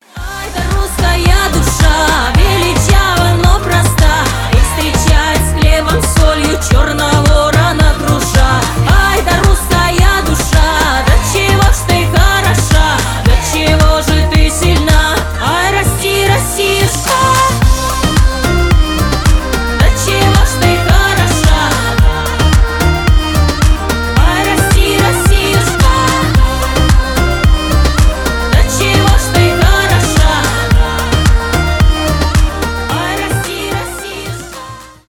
поп
фолк